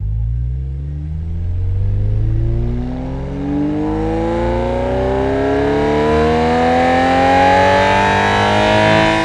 v12_02_Accel.wav